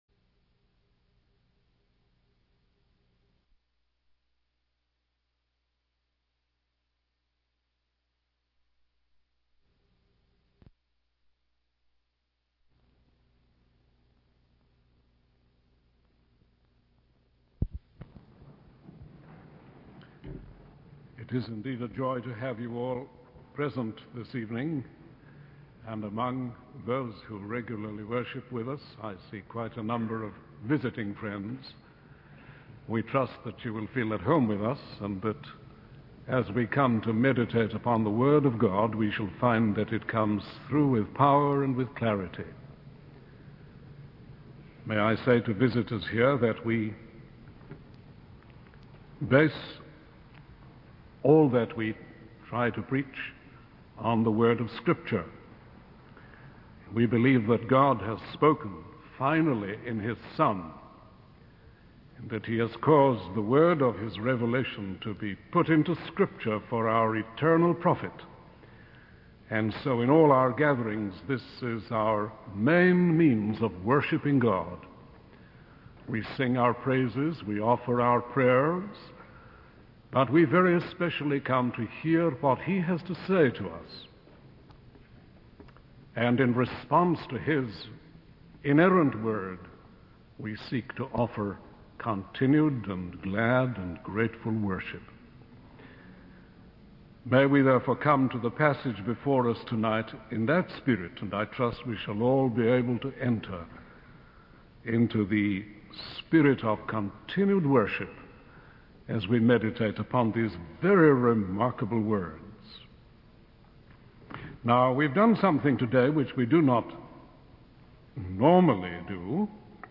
In this sermon, the preacher discusses the importance of living a life that pleases God, as there will be a judgment day when we will have to answer for our actions. The preacher emphasizes that our ultimate goal should be to bring pleasure to God, rather than seeking our own pleasure in this life. He highlights the fact that God is just and holy, and on the day of judgment, we will be rewarded or held accountable for our actions.